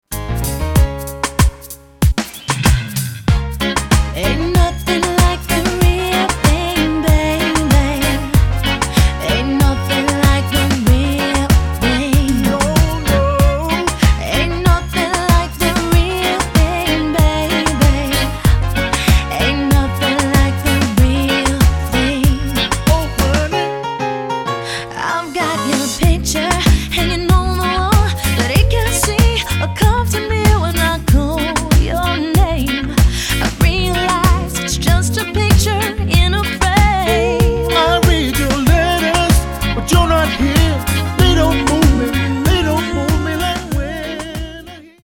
Genre: Contemporary.